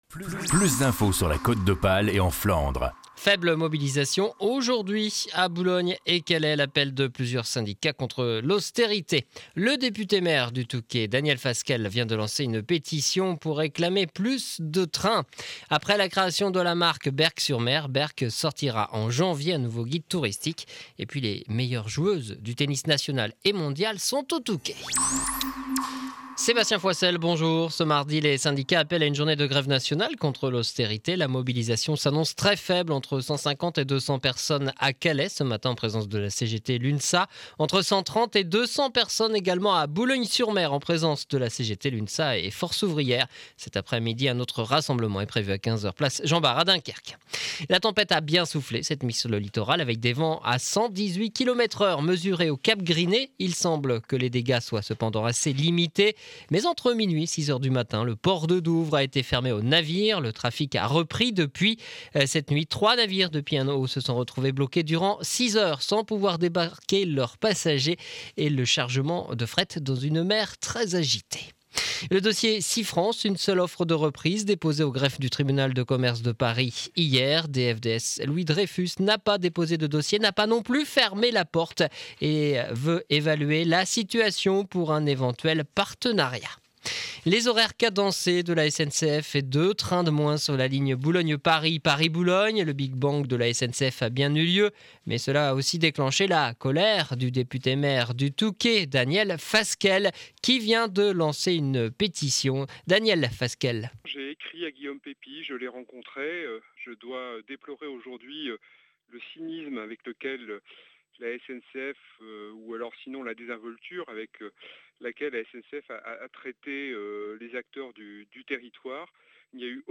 journal du mardi 13 décembre édition de 12h à Montreuil sur mer